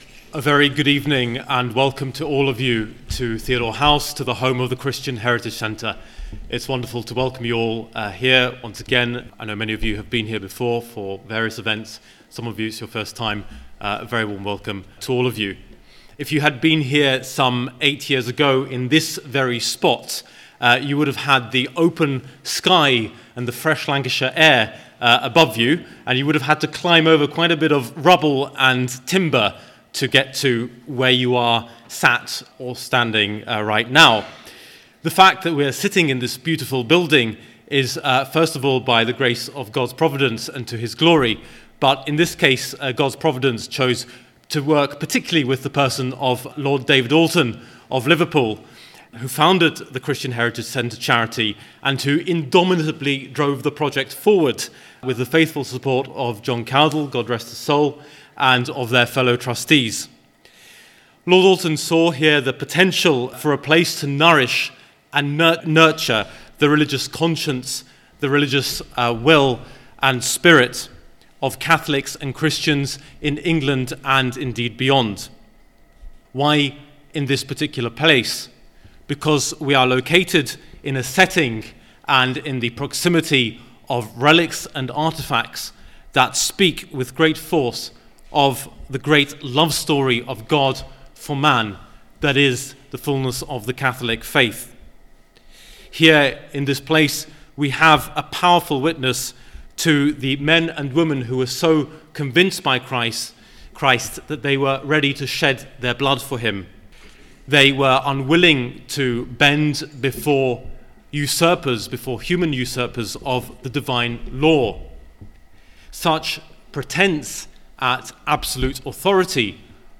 A lecture
introduced by the Lord Alton of Liverpool